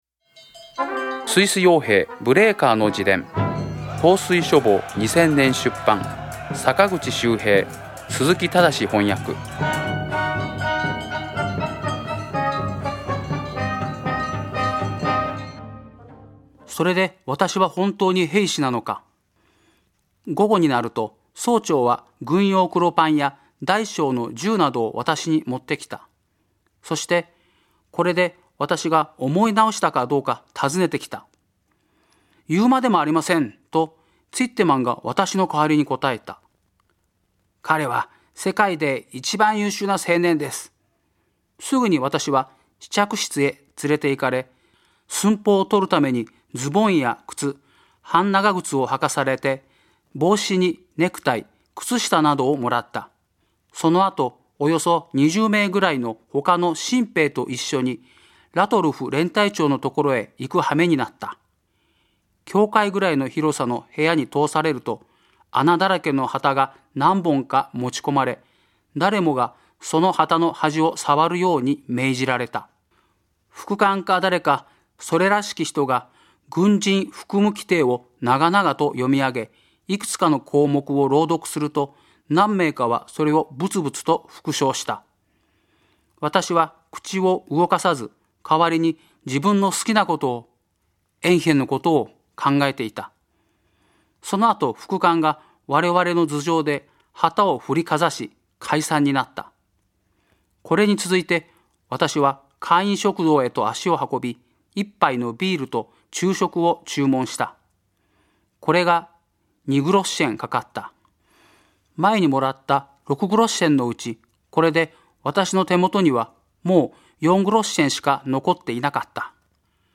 朗読『スイス傭兵ブレーカーの自伝』第49回